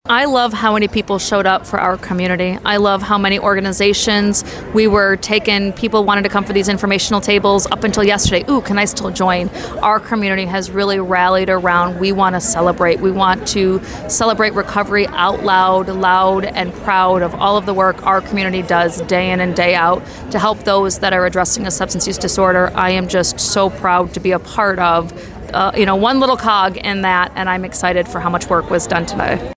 Jackson County Prosecutor Kelsey Guernsey says that she was happy to see the response the PATH Summit received from the Jackson community.